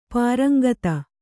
♪ pāraŋgata